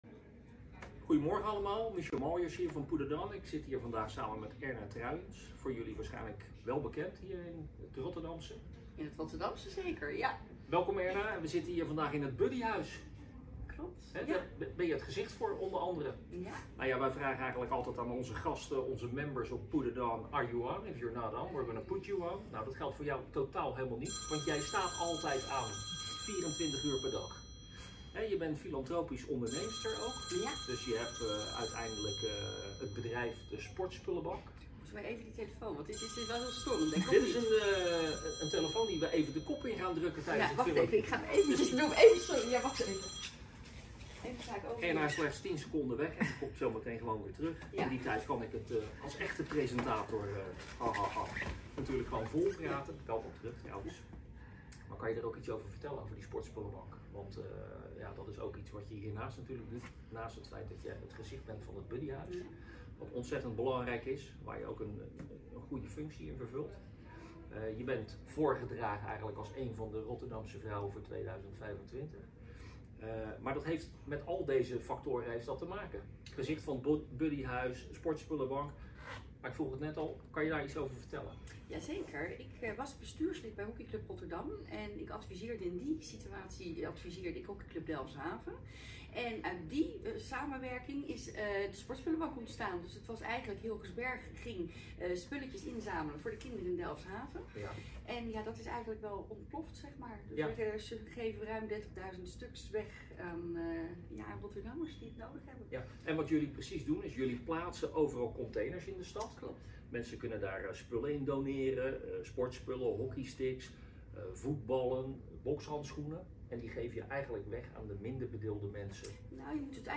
A great talk